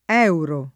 $uro] s. m. («moneta») — inv. nel pl. secondo l’uso uffic. (essendo stato trattato, senza un’adeguata motivaz., come un 1° elem. di parola composta): somma espressa in lire e in euro (fam. e in euri) — masch. anche nel pl.: quanti euro anticipati (err. quante euro anticipate: spiegabile con l’attraz. di quante lire per il senso di quante auto per l’uscita in -o inv.)